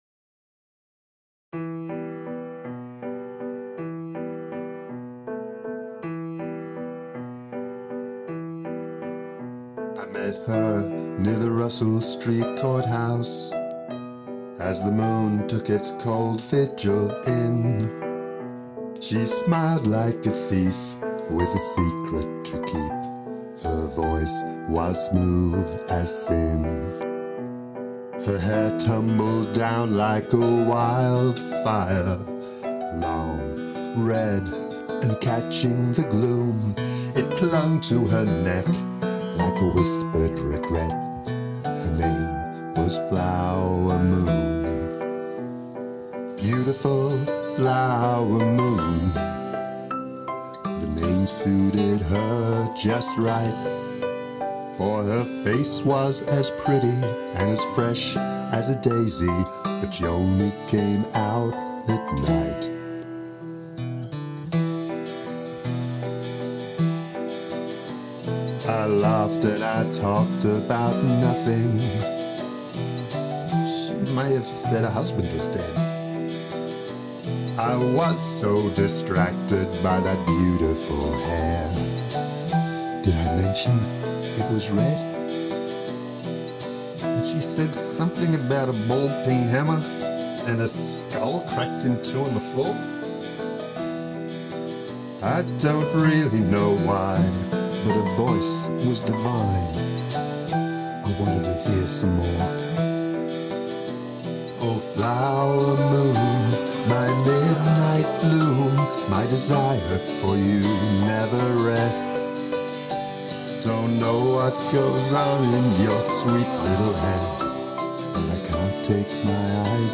Narrative song about a murder